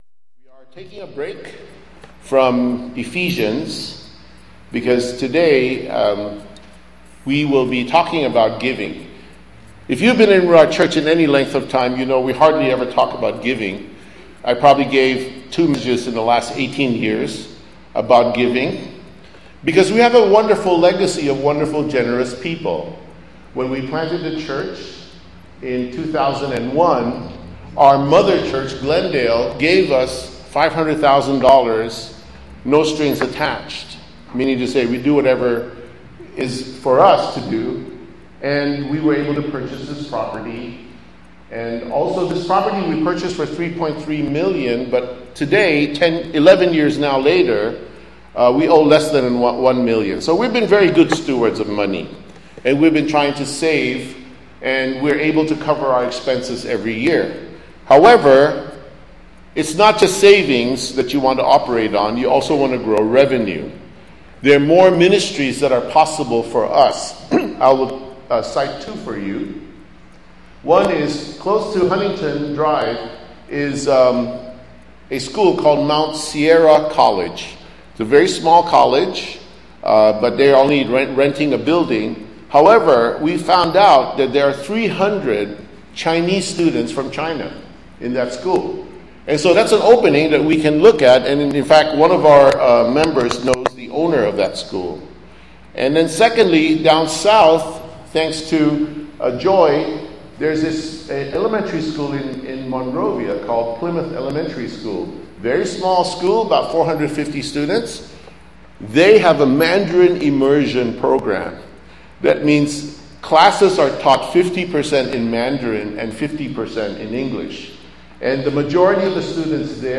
Sermon Topics: Tithing